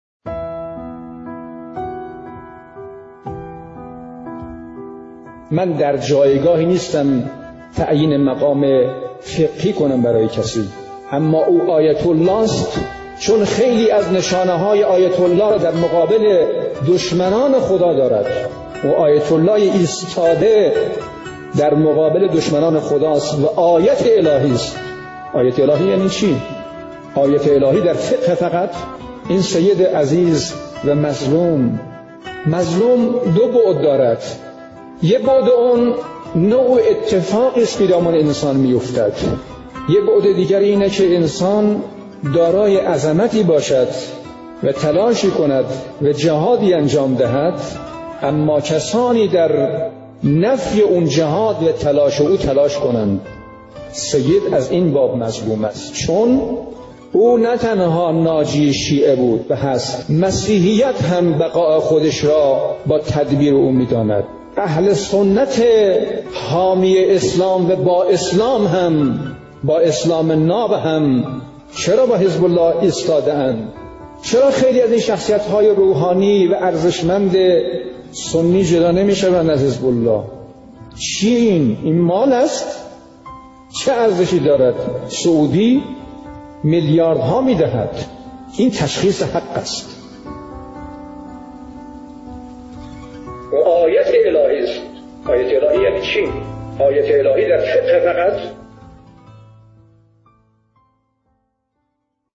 بیانات شنیدنی شهید حاج قاسم سلیمانی در خصوص جایگاه و شخصیت شهید سید حسن نصرالله ...